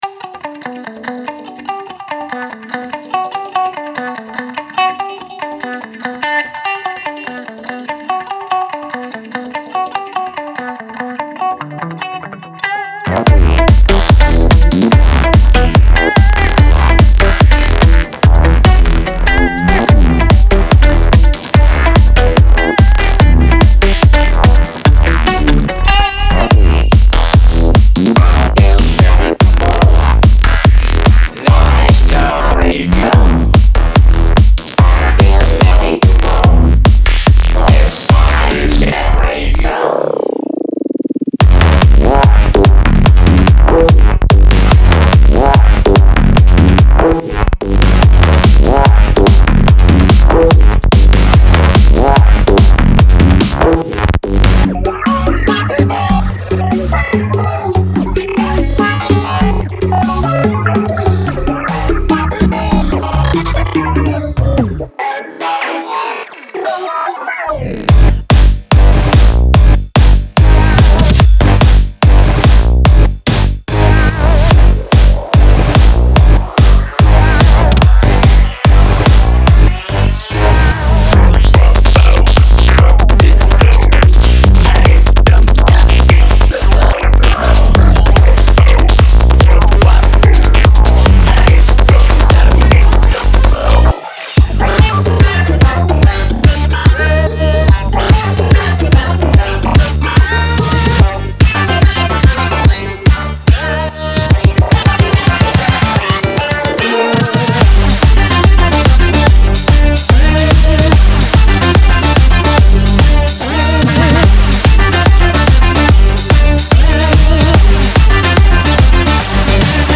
•140-145 BPM